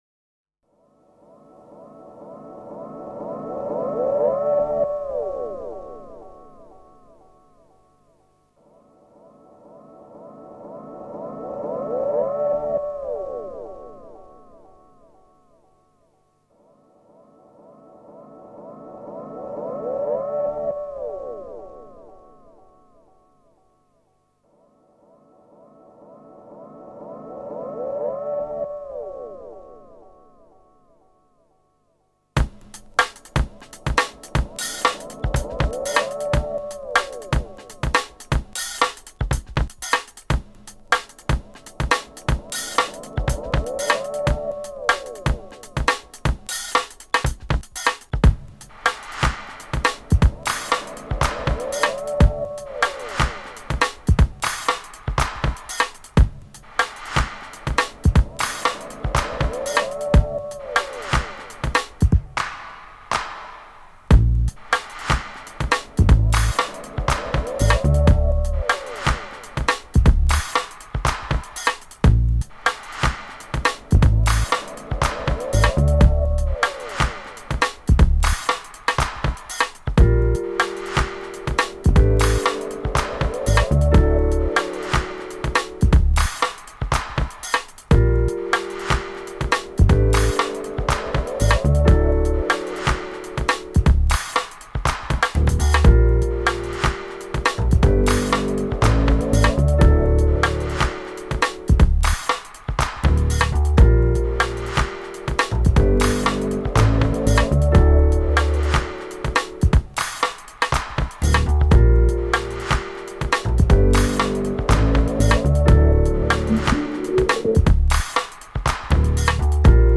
A downtempo electronica track. It begins with some spacey, slow-beat synthesized sounds echoing into the distance.